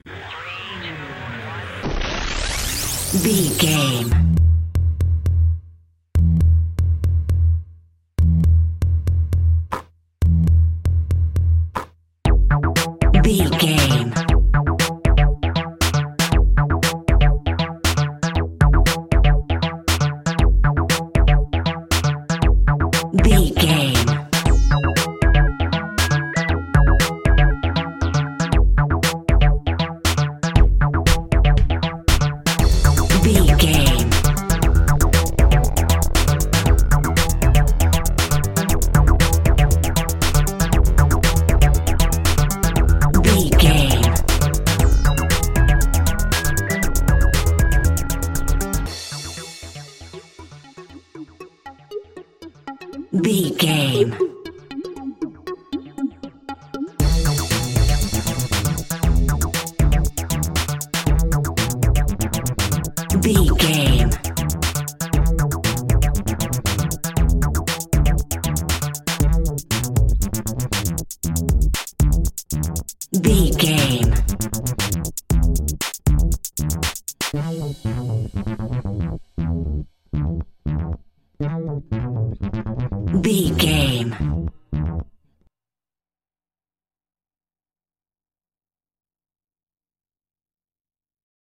Retro Ninetees Arcade Music Theme.
Aeolian/Minor
electronic
techno
synths
glitch
synth lead
synth bass